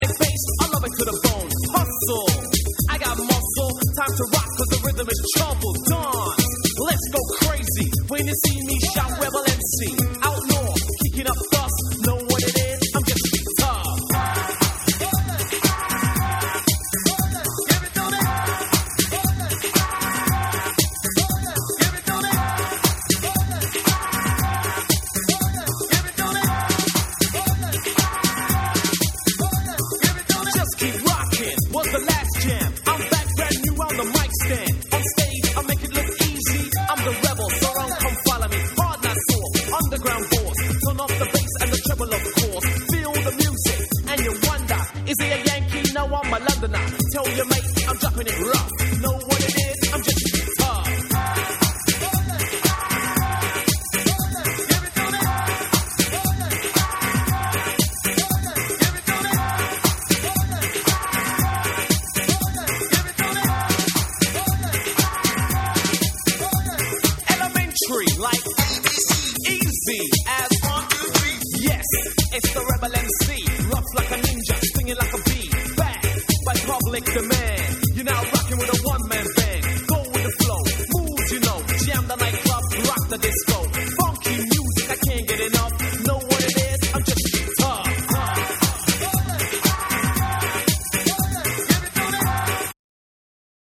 裏打ちを効かせたグラウンド・ビートに仕立てた1は
BREAKBEATS